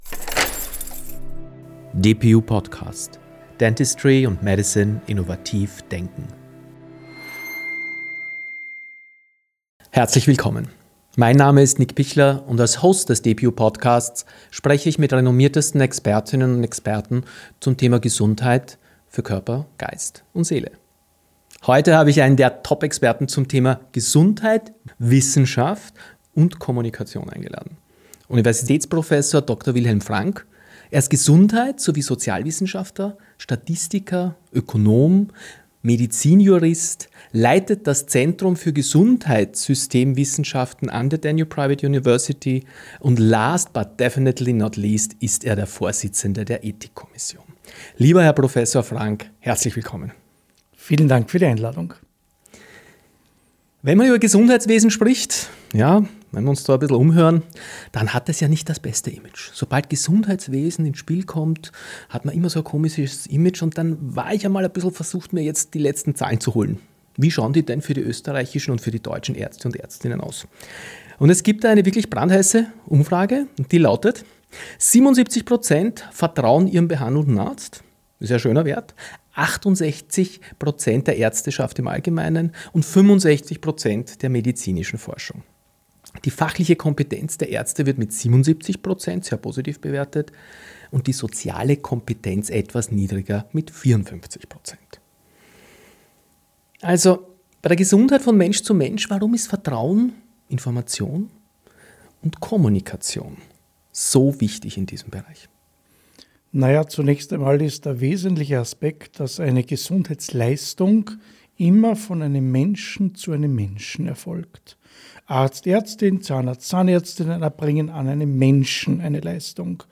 Ein Gespräch über die Rolle von Kommunikation in der Arzt-Patienten-Beziehung, über die Verantwortung von Patient*innen, über Herausforderungen durch demografische Entwicklungen – und über die Zukunft hochinnovativer, aber extrem teurer Therapien.